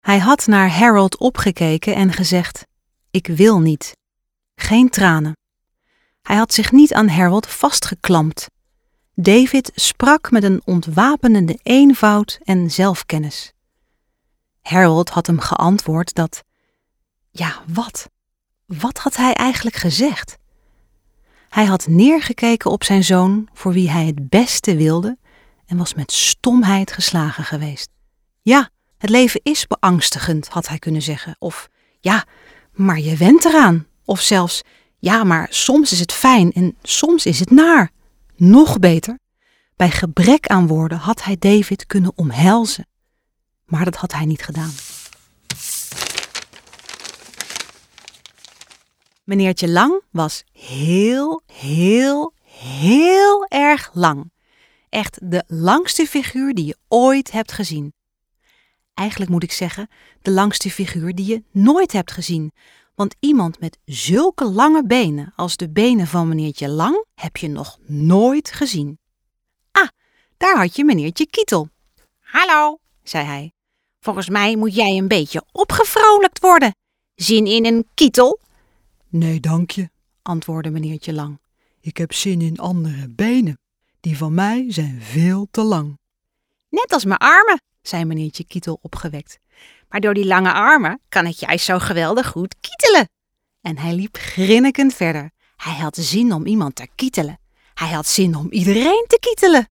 Demo Luisterboek
Bestanden worden ‘schoon’ aangeleverd.
Meeslepend en pakkend, met een stem die kristalhelder, fluweel verleidelijk, emotioneel hees en regerend dominant kan zijn.